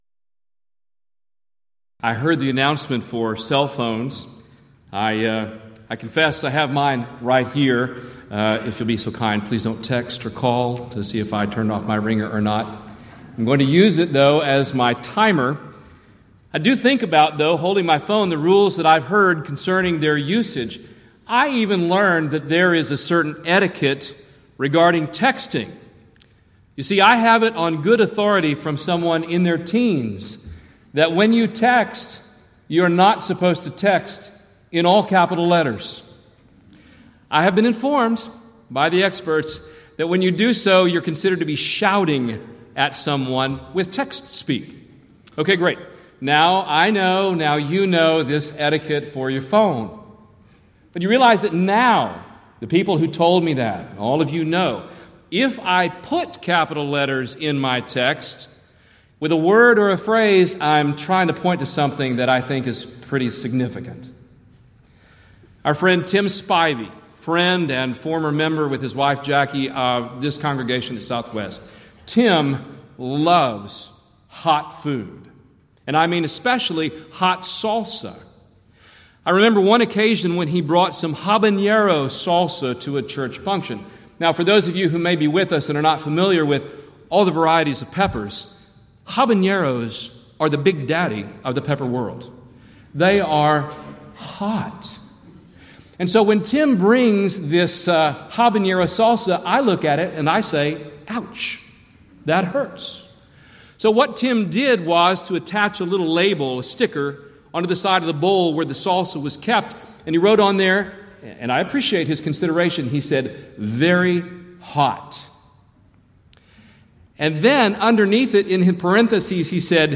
Event: 33rd Annual Southwest Lectures
lecture